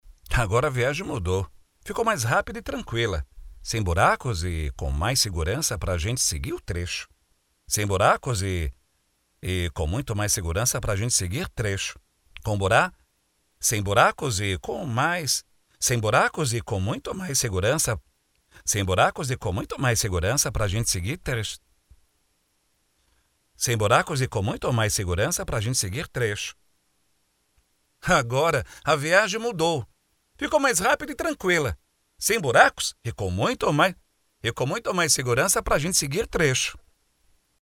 ATENÇÃO: A Narração precisa soar natural, a ideia é que soe autêntico, com uma dicção clara, mas sem o polimento de um locutor de estúdio, focando na praticidade do benefício (economia de tempo e dinheiro).